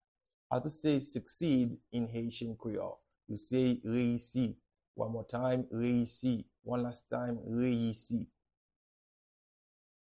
Pronunciation and Transcript:
How-to-say-Succeed-in-Haitian-Creole-Reyisi-pronunciation.mp3